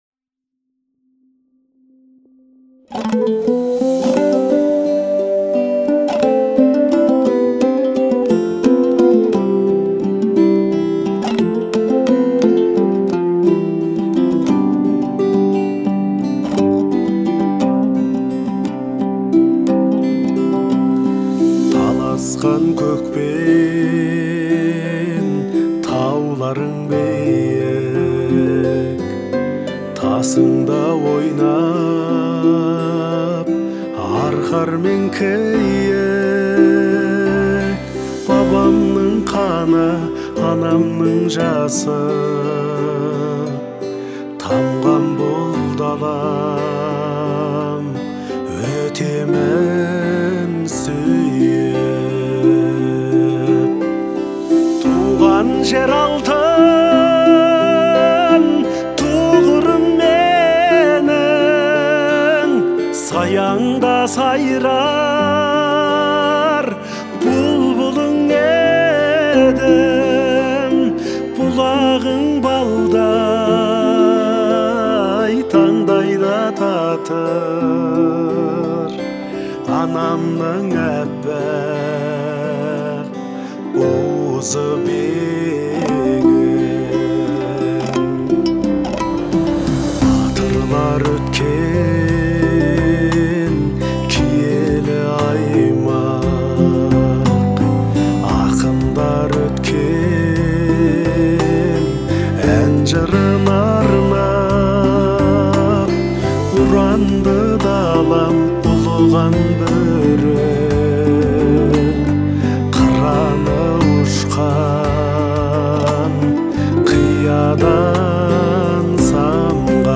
это трогательная песня в жанре казахской народной музыки